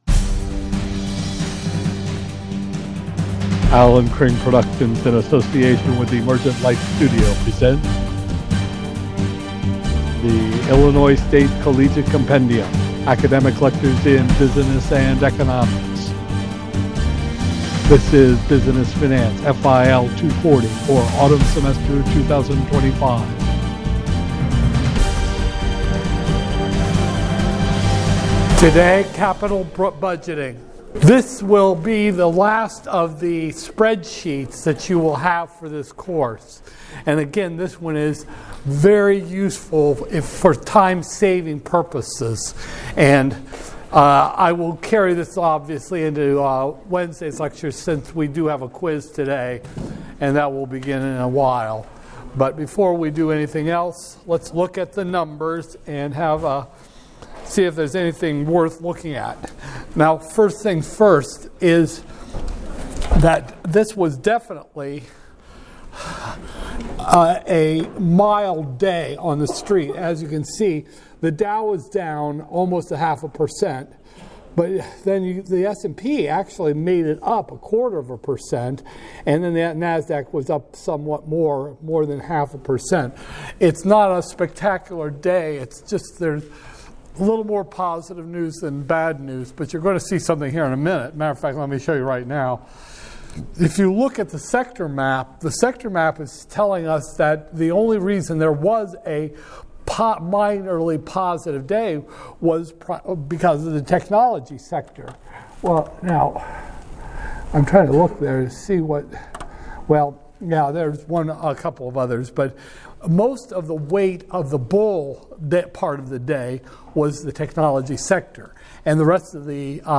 Business Finance, FIL 240-001, Spring 2025, Lecture 21